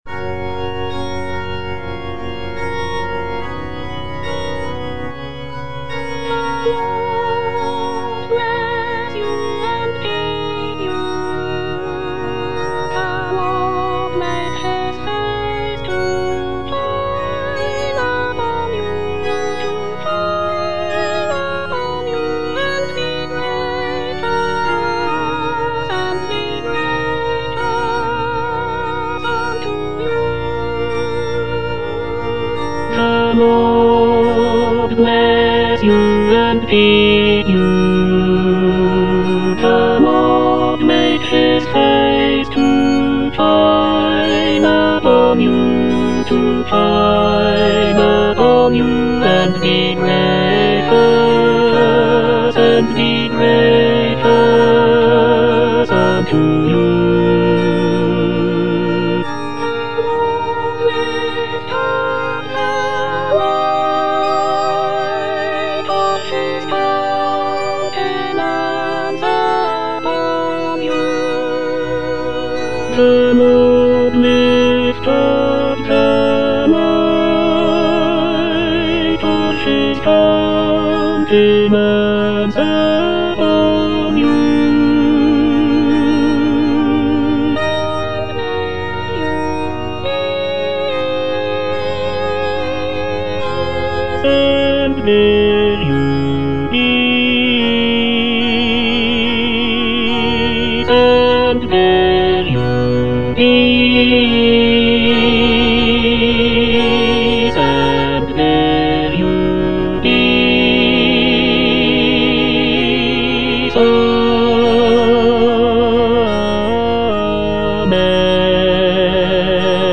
Tenor (Emphasised voice and other voices)
choral benediction